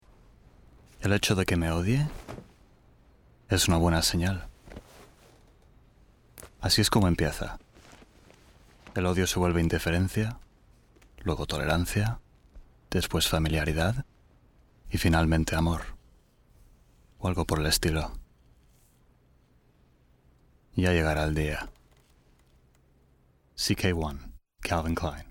kastilisch
Sprechprobe: Sonstiges (Muttersprache):
Native Castilian Spanish actor with over 10 years of professional voiceover credits in TV and radio ads, audioguides, corporate films, videogames, eLearning and language courses.